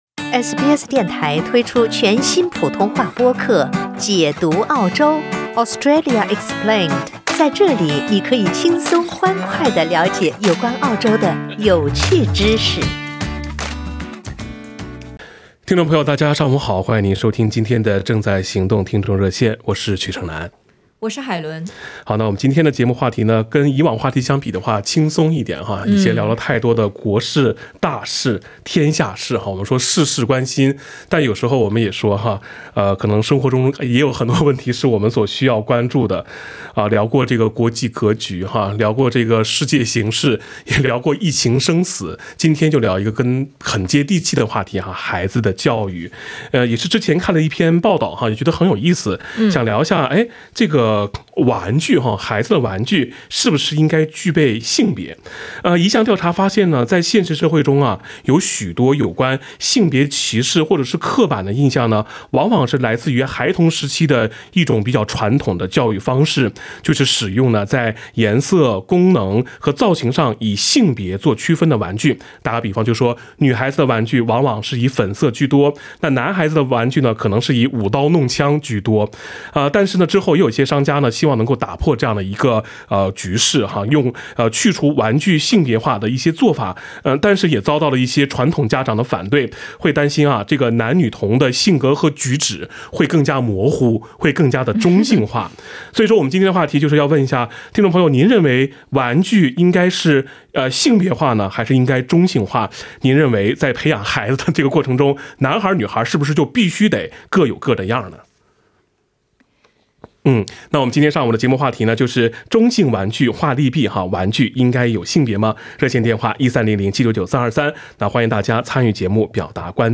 （带点击封面图片，收听热线回放）